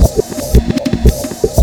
FXLOOPREV1-R.wav